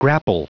Prononciation du mot grapple en anglais (fichier audio)
Prononciation du mot : grapple